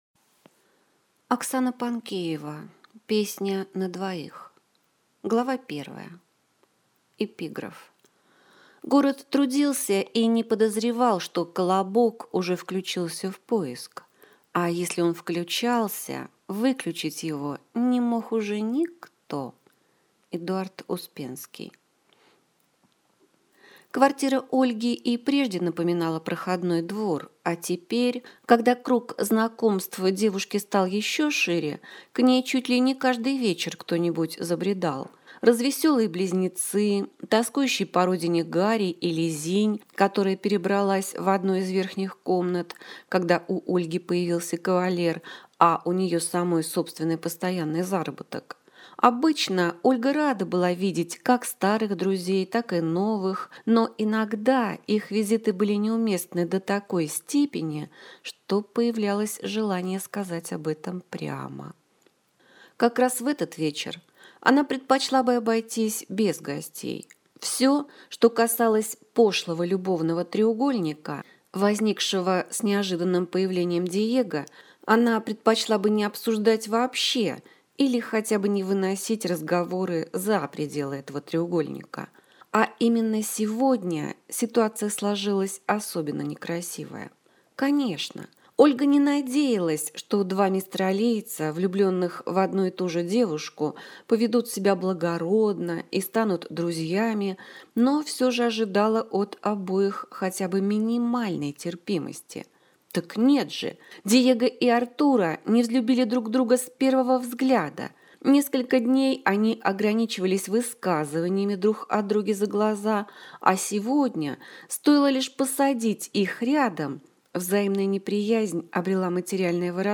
Аудиокнига Песня на двоих | Библиотека аудиокниг